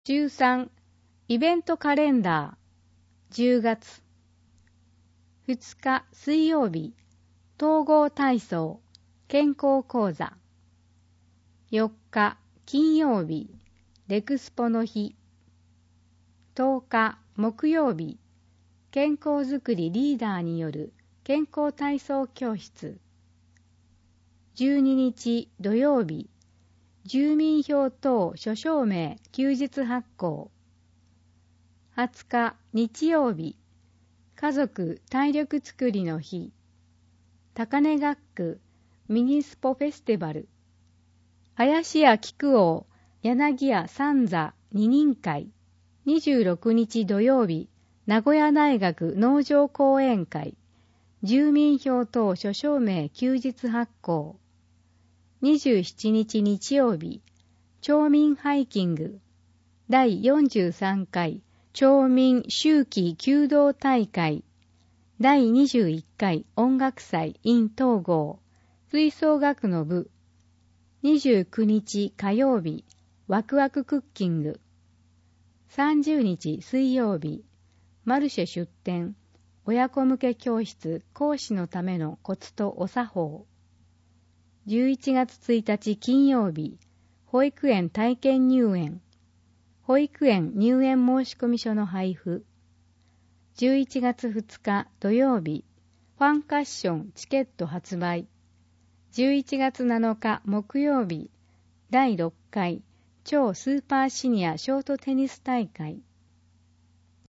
広報とうごう音訳版（2019年10月号）